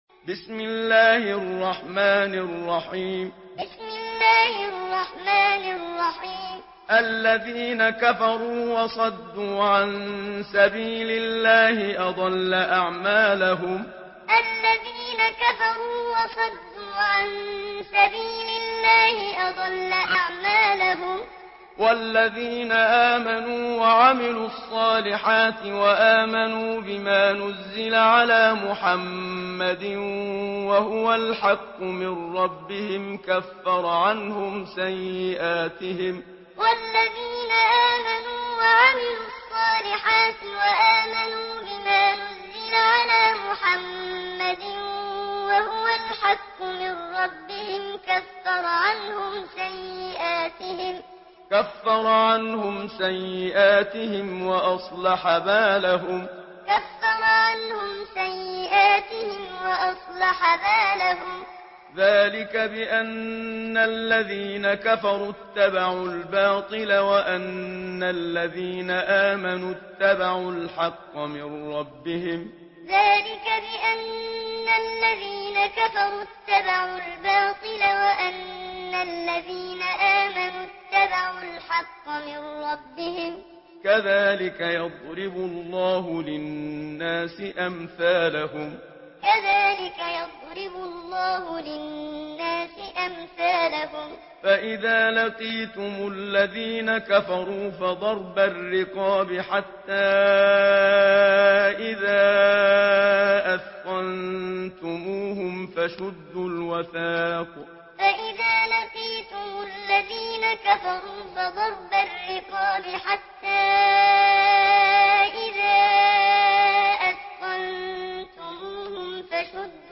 Surah Muhammad MP3 in the Voice of Muhammad Siddiq Minshawi Muallim in Hafs Narration
Surah Muhammad MP3 by Muhammad Siddiq Minshawi Muallim in Hafs An Asim narration.